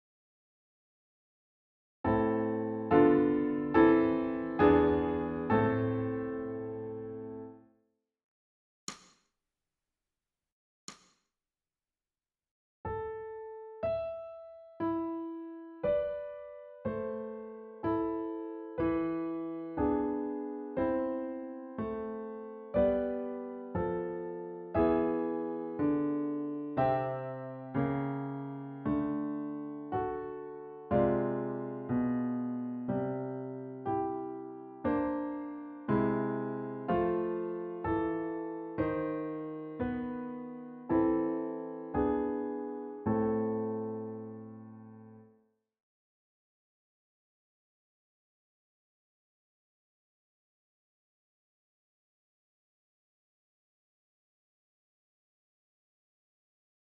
ソルフェージュ 聴音: 2-4-09